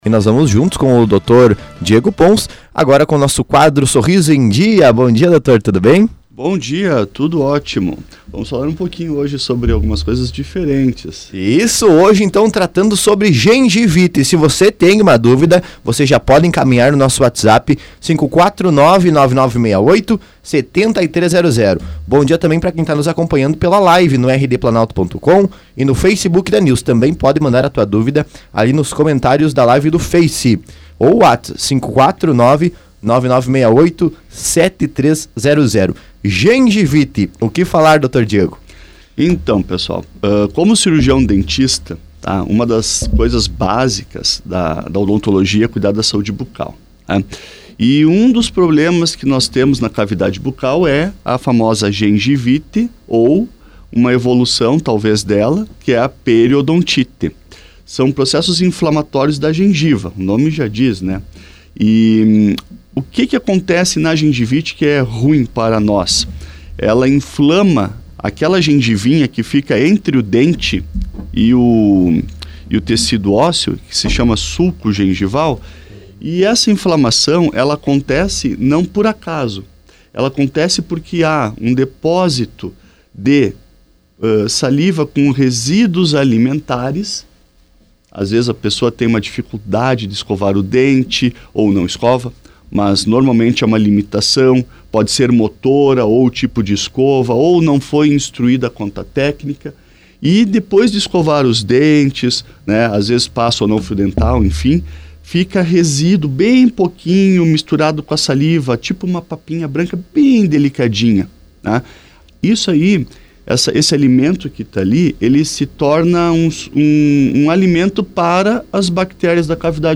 O quadro “Sorriso em Dia”, transmitido todas as quintas-feiras pela Planalto News FM 92.1, abordou nesta quinta-feira (10), um problema bastante comum, mas muitas vezes ignorado: a gengivite.